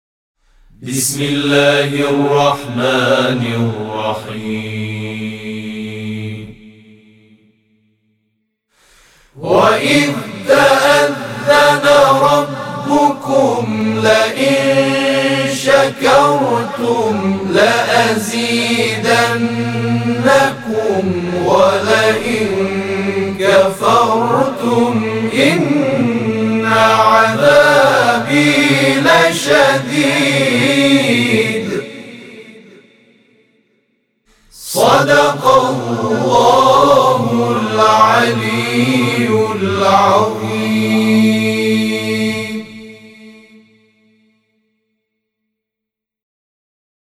صوت همخوانی آیه 7 سوره ابراهیم از سوی گروه تواشیح «محمد رسول‌الله(ص)»